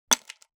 ice fall 26.wav